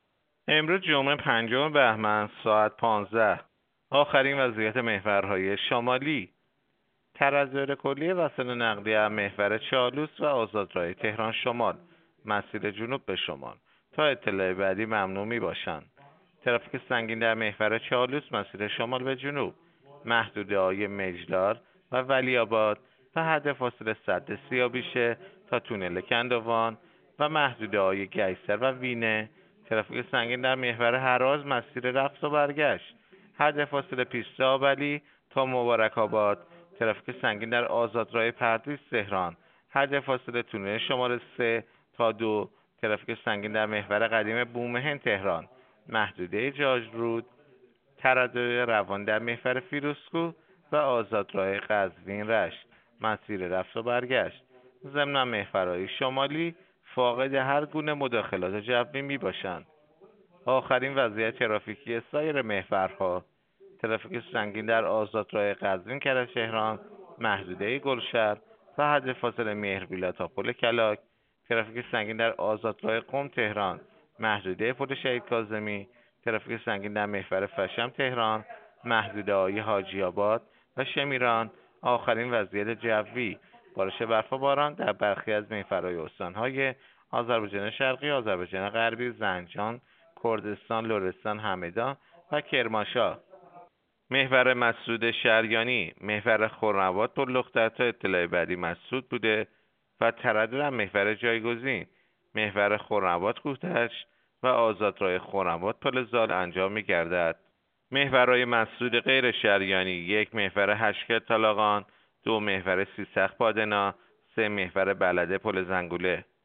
گزارش رادیو اینترنتی از آخرین وضعیت ترافیکی جاده‌ها ساعت ۱۵ پنجم بهمن؛